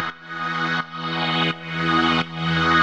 Index of /musicradar/sidechained-samples/170bpm
GnS_Pad-alesis1:2_170-E.wav